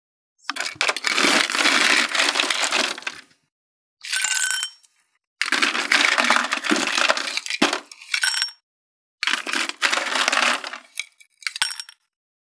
42.氷をコップに入れる【無料効果音】
ASMR/ステレオ環境音各種配布中！！
ASMRコップ効果音